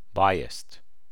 106_biased.ogg